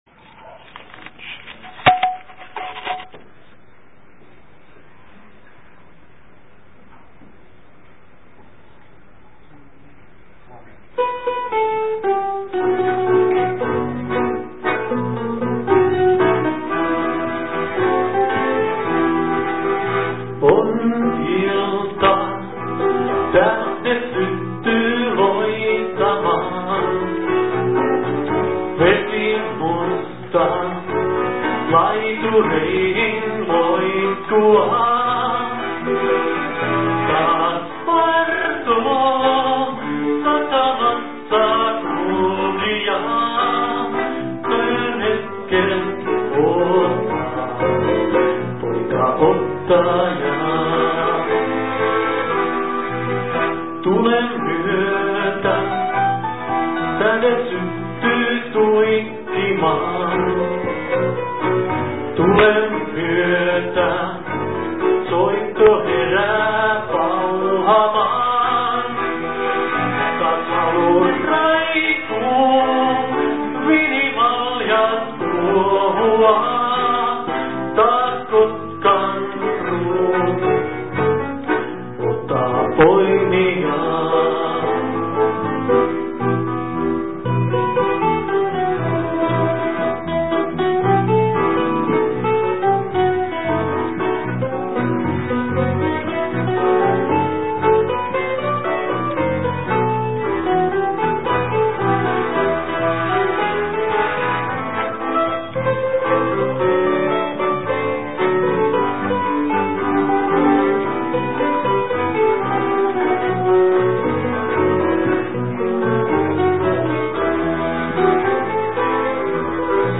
Bass Guitar
above - a large audience came to enjoy the evening of music and comedy